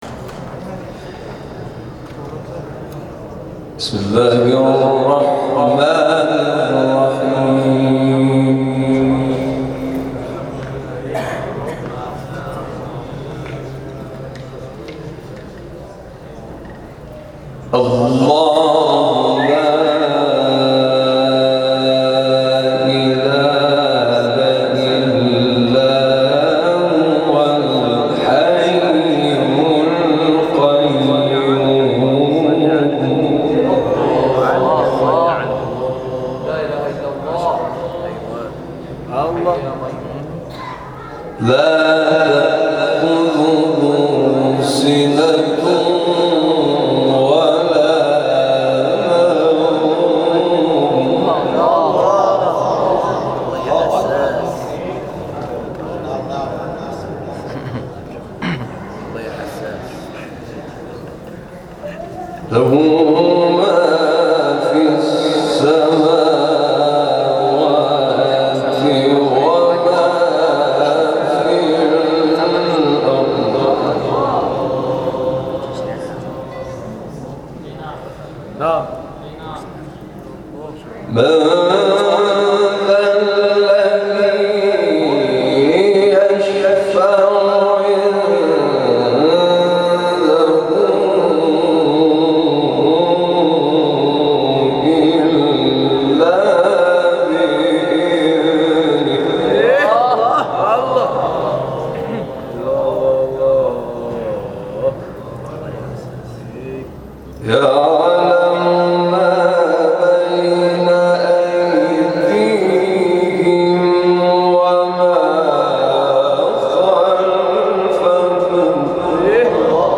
گردهمایی سالیانه قاریان شهرری و گرامیداشت اساتید فقید + صوت و عکس